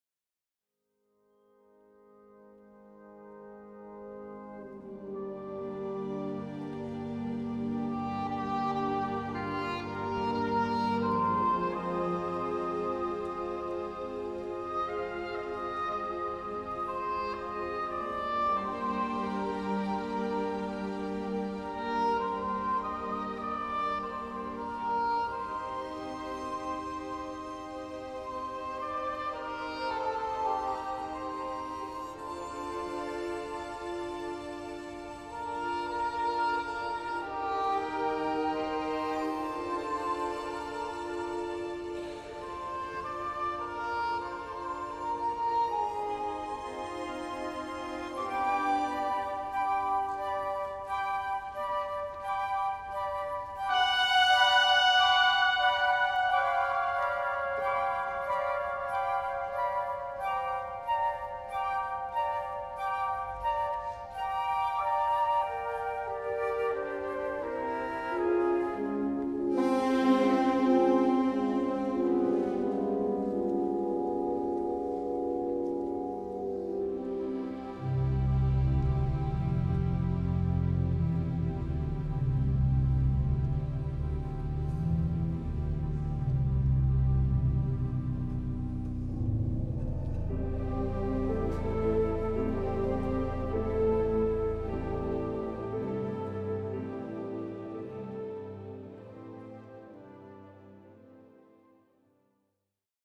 St Peter's, Eaton Square, London